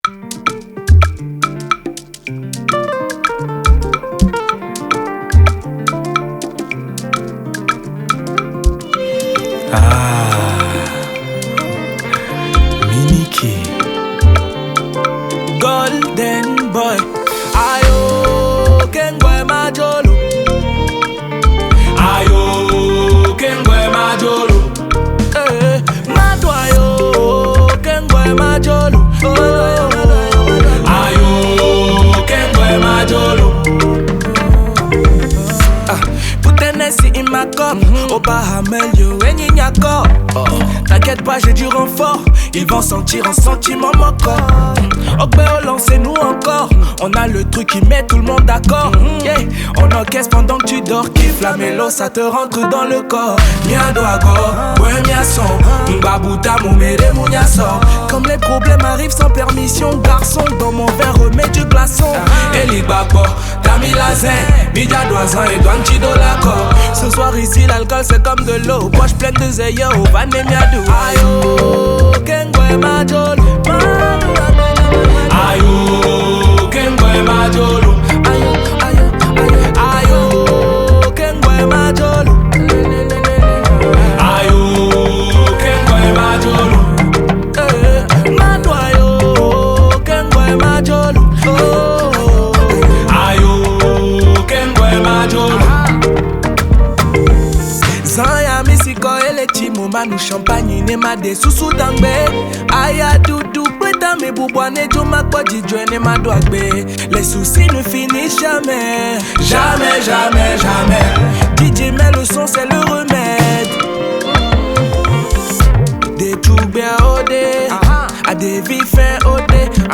Afrobeat
With its upbeat tempo and catchy sounds